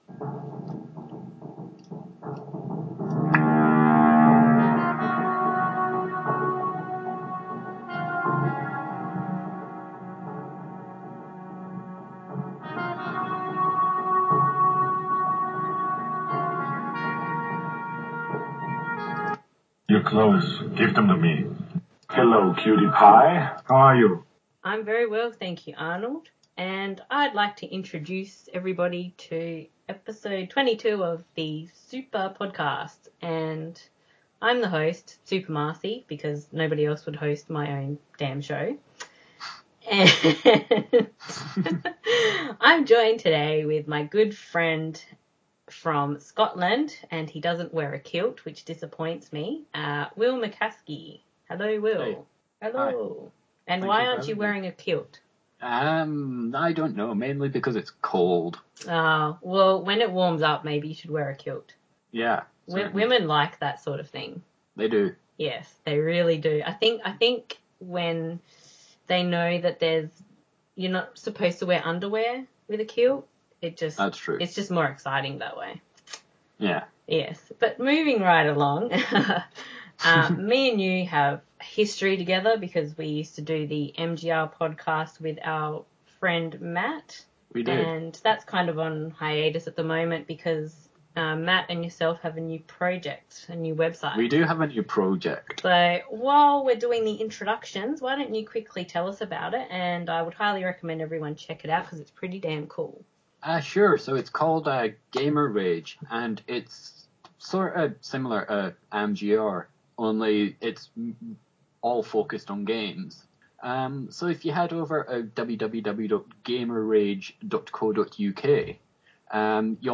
Our thoughts on The Last Action Hero. Which films are better the 80’s/early 90’s or the mid 90’s? And yes there are impressions baby!